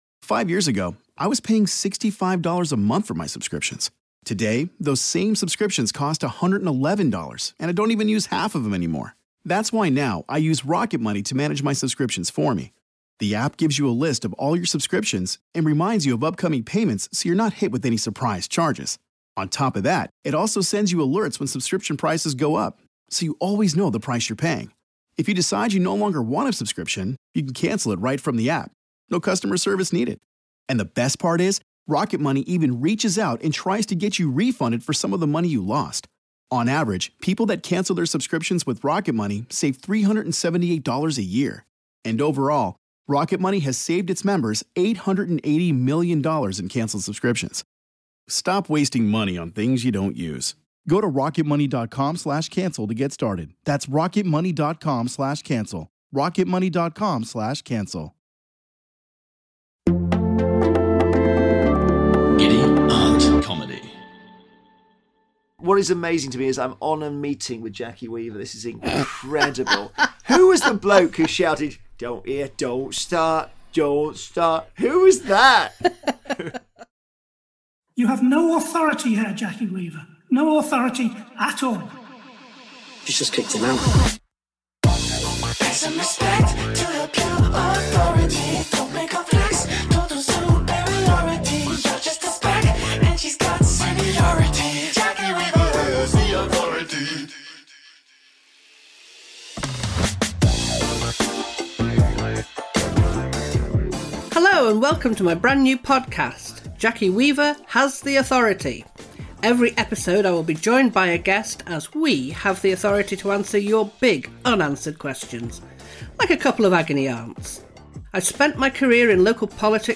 Broadcasting legend and cycling fiend - Jeremy Vine - joins Jackie to answer life's big unanswered questions.